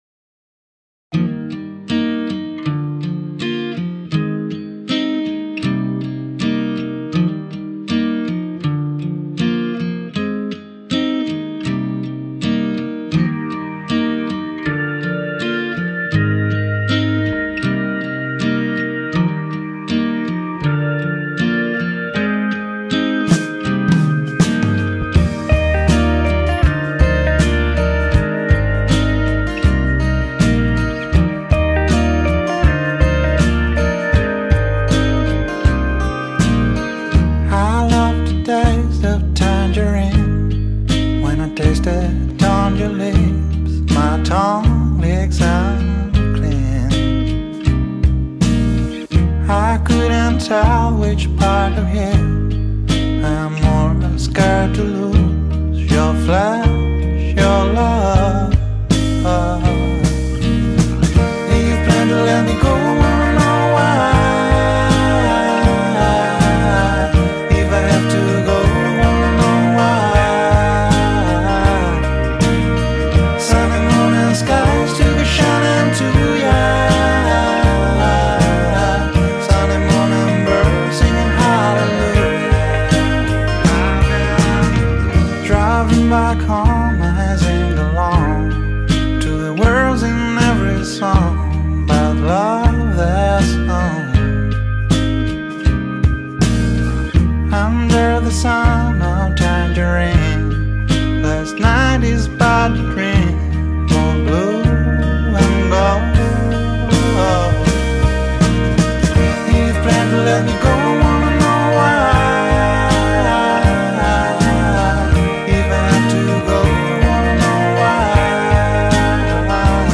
sunday_morning_birds.mp3